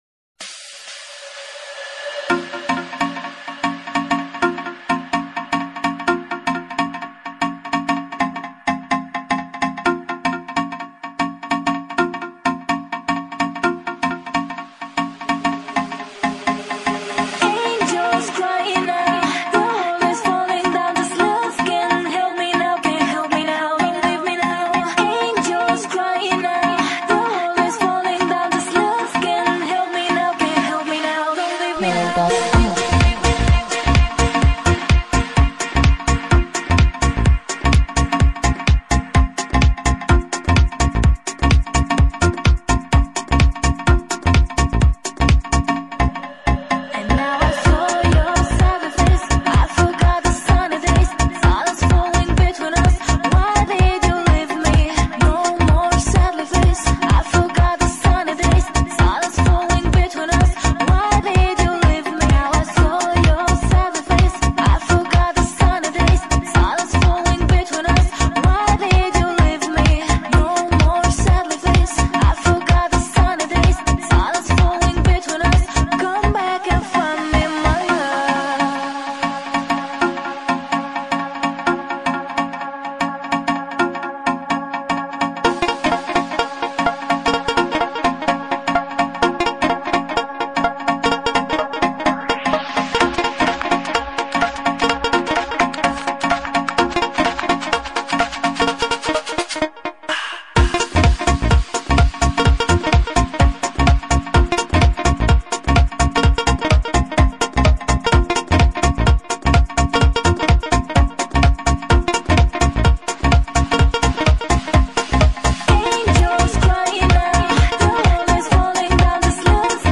Жанр:Club/Dance